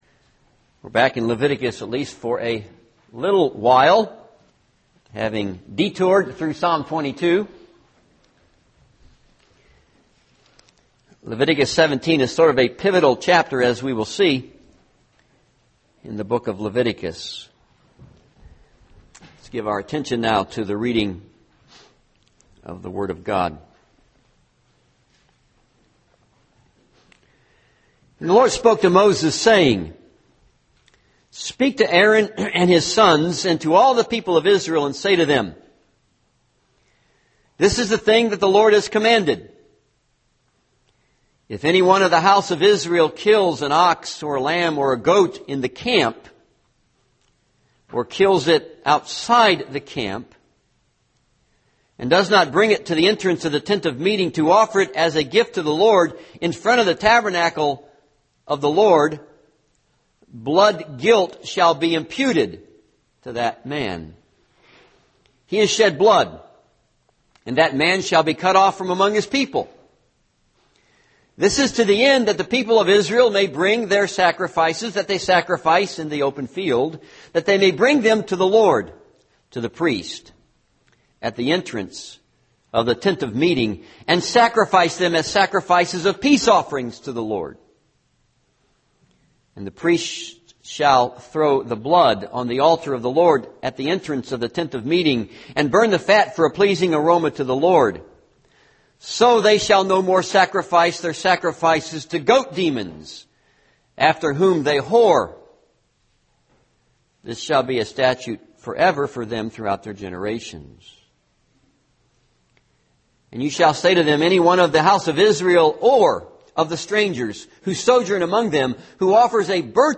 This is a sermon on Leviticus 17.